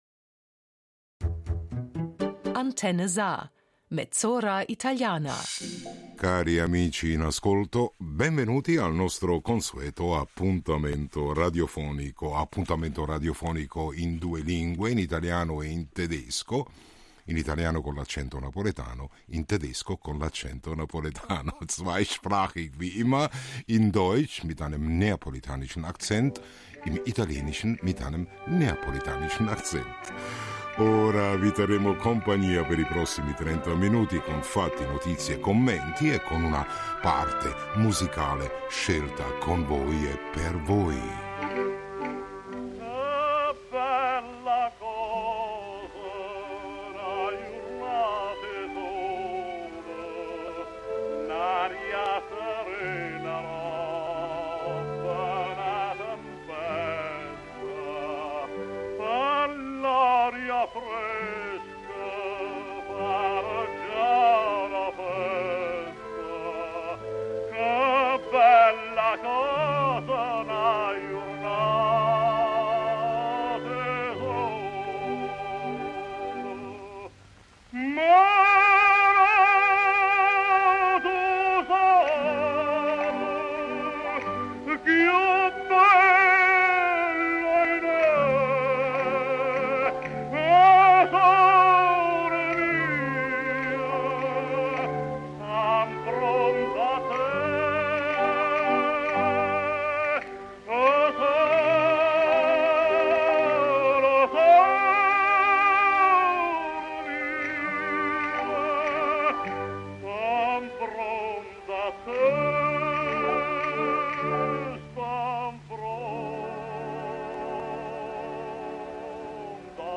Tema: Teatro italiano in Lussemburgo, letteratura italiana a Francoforte. Intervista